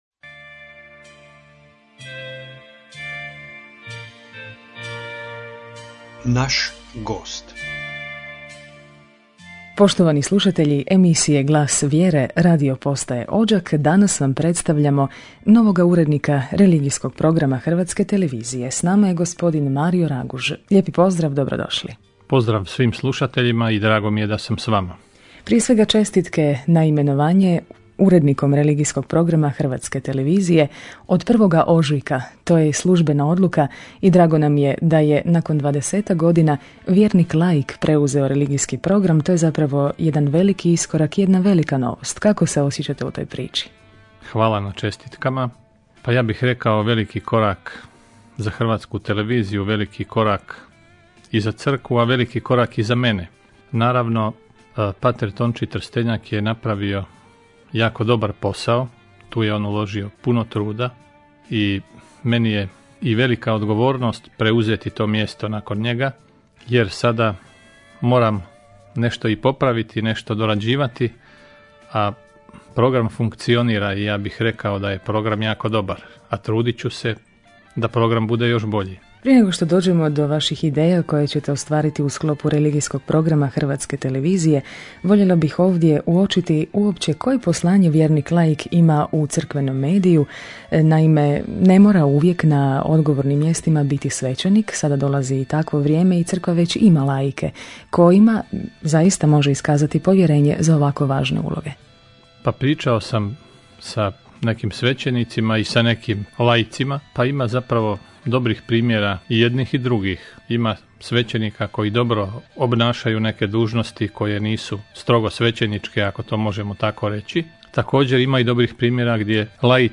Audio: Razgovor